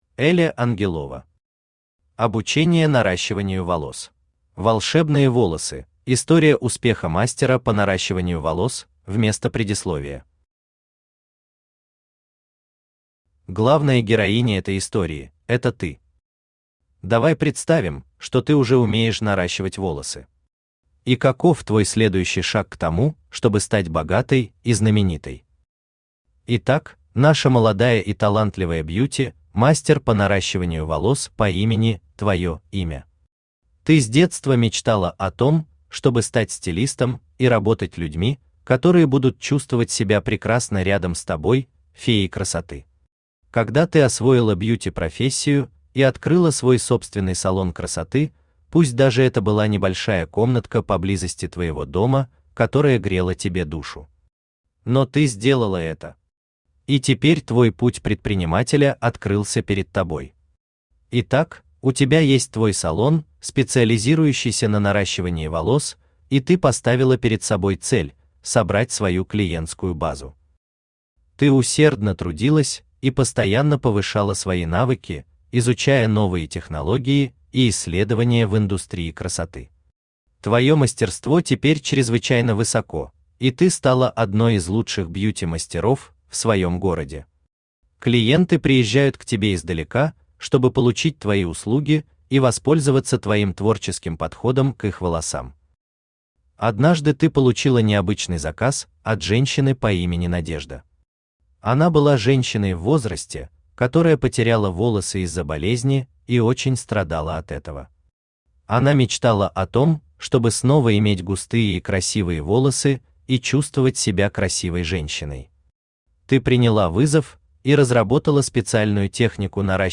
Аудиокнига Обучение наращиванию волос | Библиотека аудиокниг
Aудиокнига Обучение наращиванию волос Автор Эля Ангелова Читает аудиокнигу Авточтец ЛитРес.